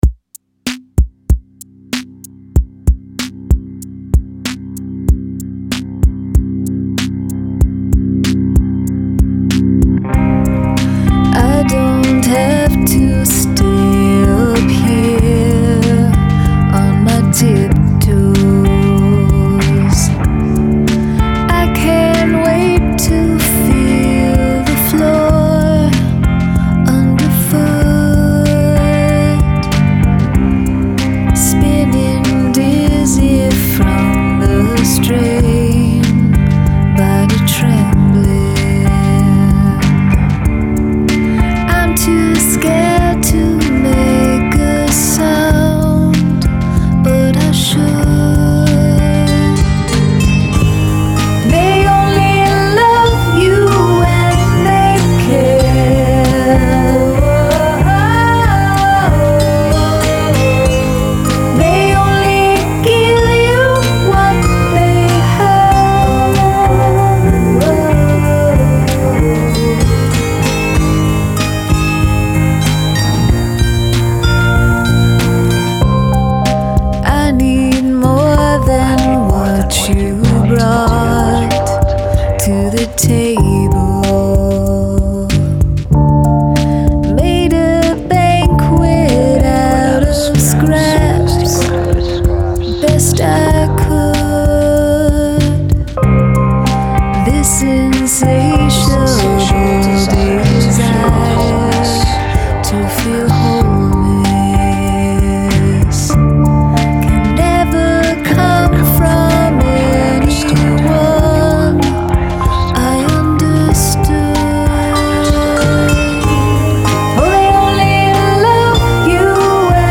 Guest spoken word